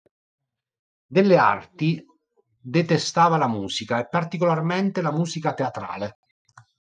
par‧ti‧co‧lar‧mén‧te
/par.ti.ko.larˈmen.te/